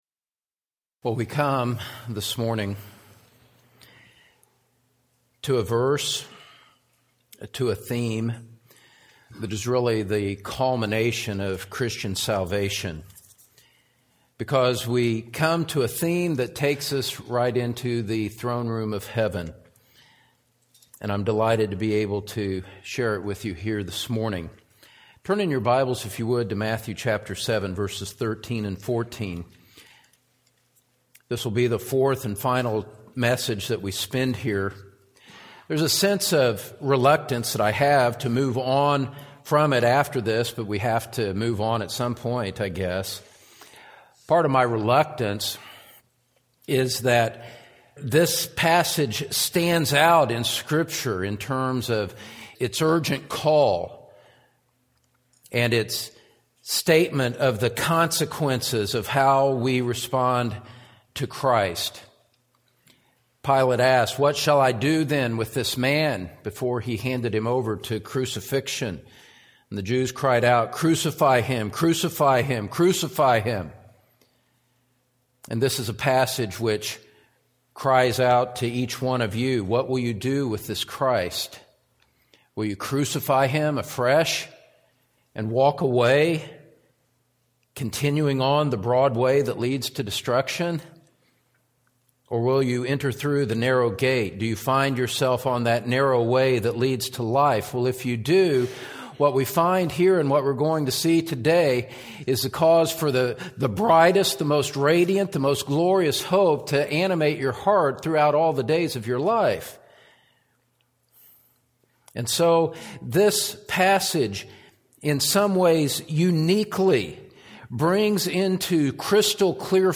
The Narrow Way to Heaven | SermonAudio Broadcaster is Live View the Live Stream Share this sermon Disabled by adblocker Copy URL Copied!